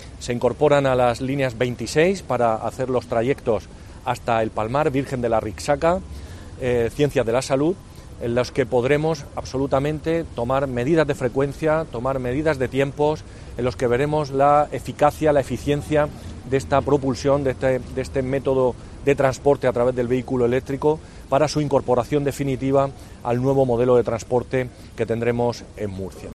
jose Francisco Múñoz, concejal movilidad